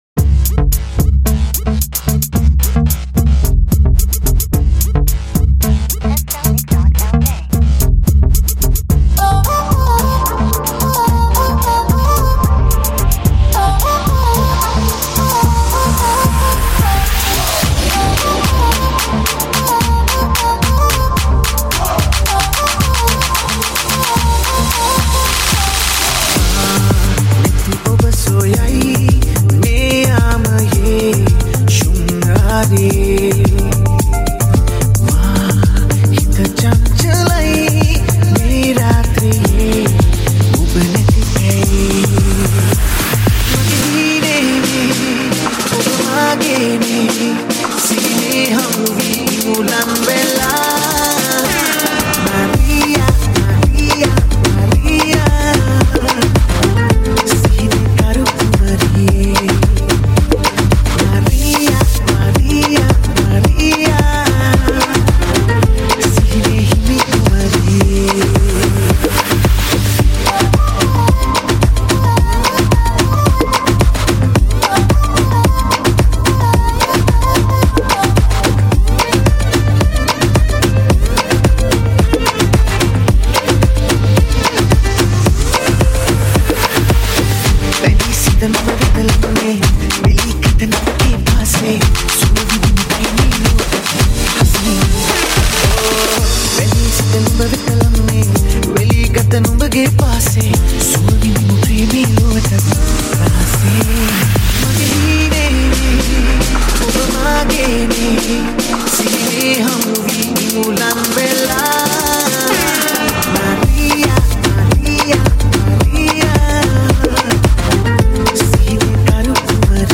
High quality Sri Lankan remix MP3 (4).